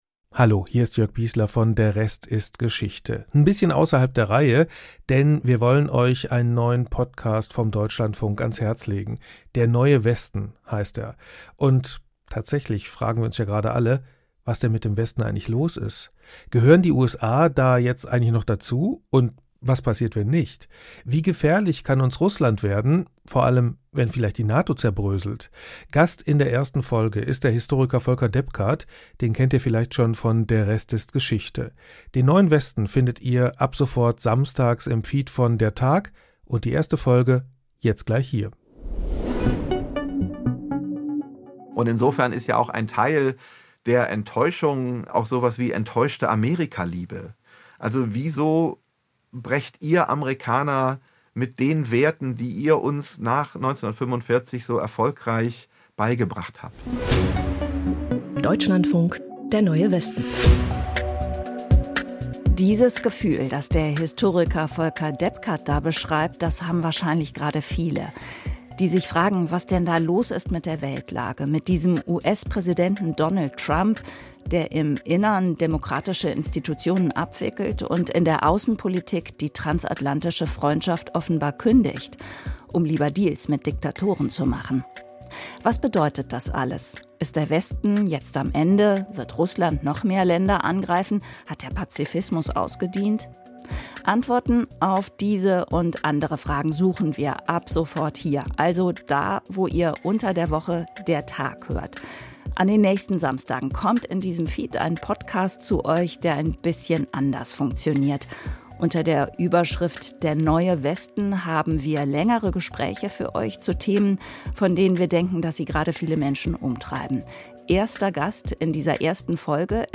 Podcast-Tipp - "Der neue Westen" - Gespräche zur Weltlage